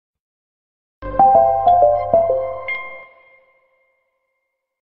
電気的な感じに少し優しいトーンを組み合わせている。